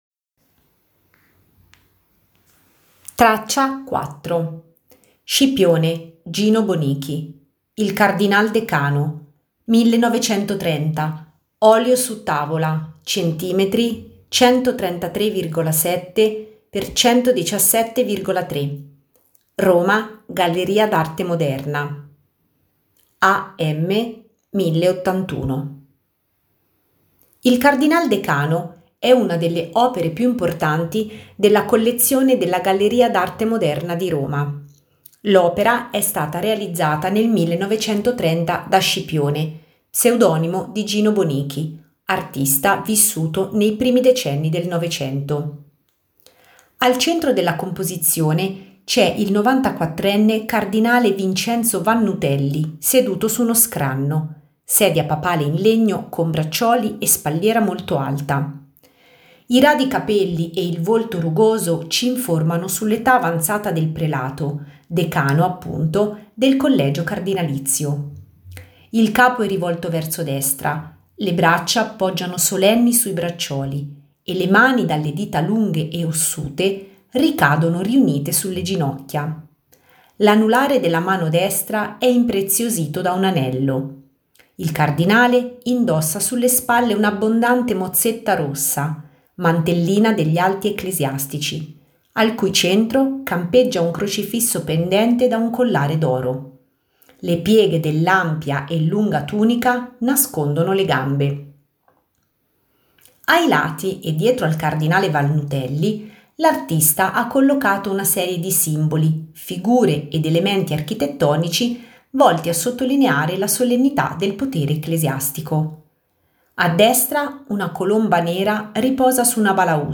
audio-descrizione